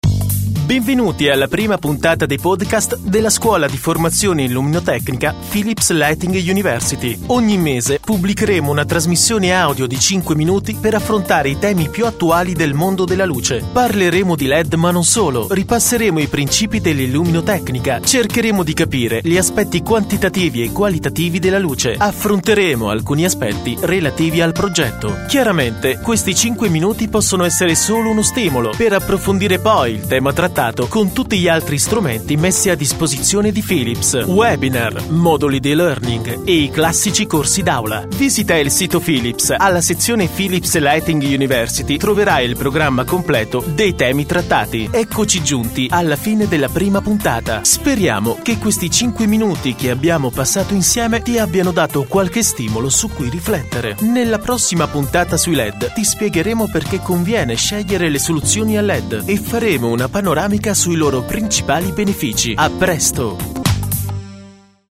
Kein Dialekt
Sprechprobe: Industrie (Muttersprache):